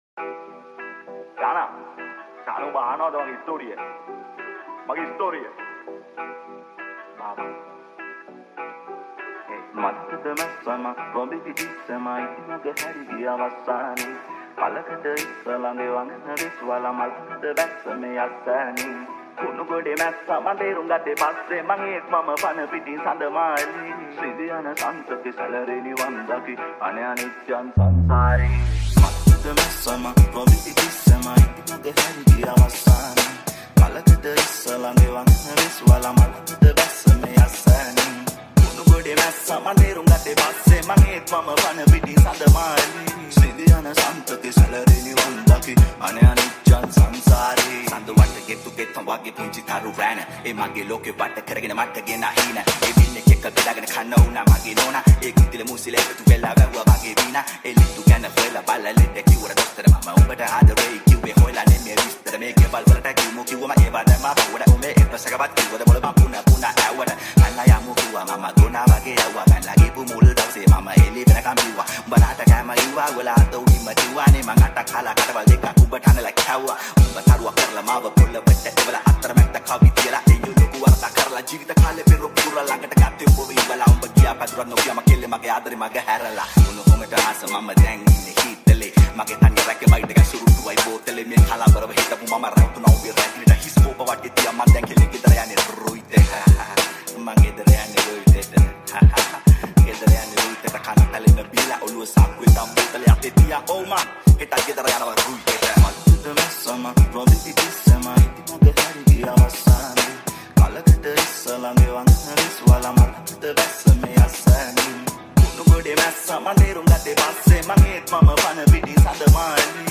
High quality Sri Lankan remix MP3 (3.3).